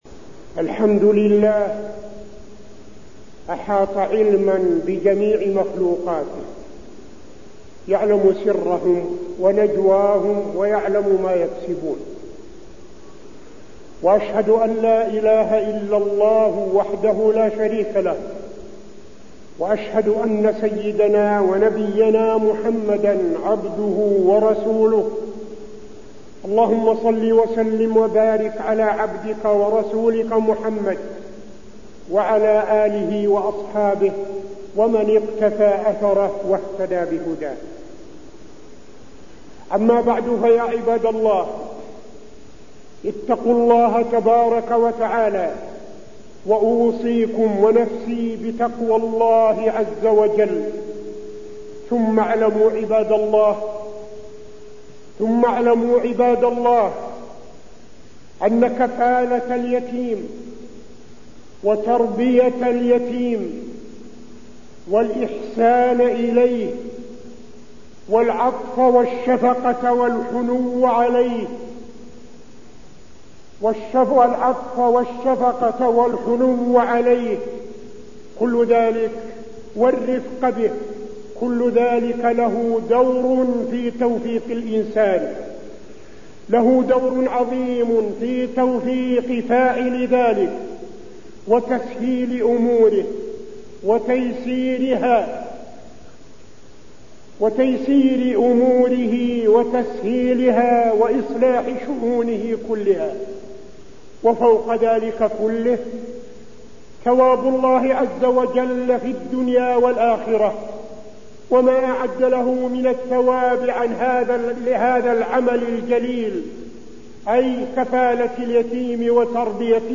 تاريخ النشر ٢٩ محرم ١٤٠٧ هـ المكان: المسجد النبوي الشيخ: فضيلة الشيخ عبدالعزيز بن صالح فضيلة الشيخ عبدالعزيز بن صالح كفالة اليتيم